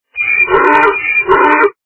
» Звуки » Природа животные » Осел - Иа-иа
При прослушивании Осел - Иа-иа качество понижено и присутствуют гудки.
Звук Осел - Иа-иа